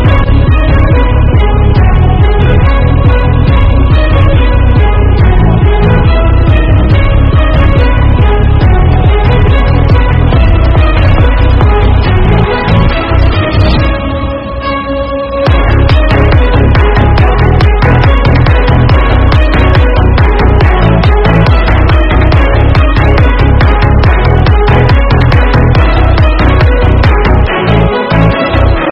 Nhạc Chuông Remix